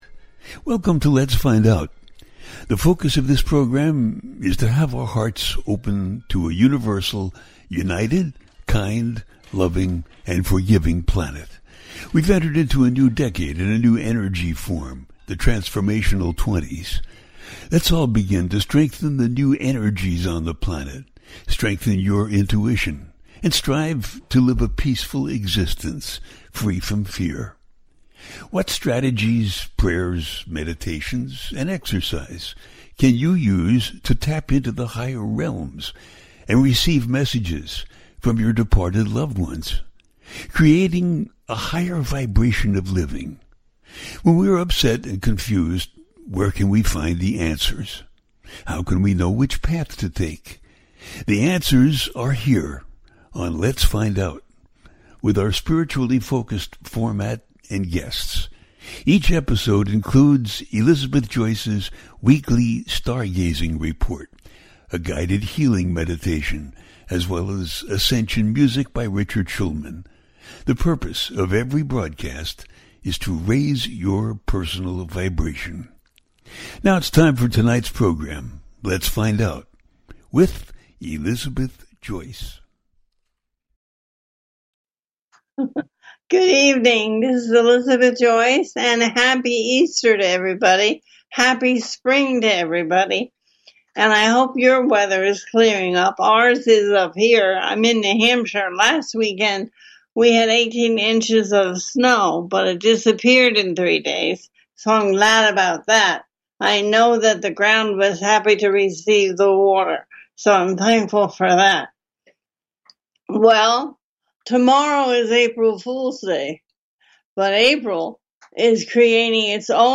Rockin’ The World In April 2024, A teaching show
The listener can call in to ask a question on the air.
Each show ends with a guided meditation.